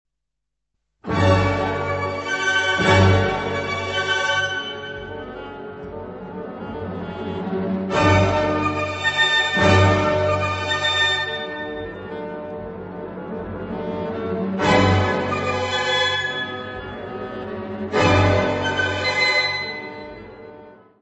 Peer Gynet, incidental music
: stereo; 12 cm + folheto
Área:  Música Clássica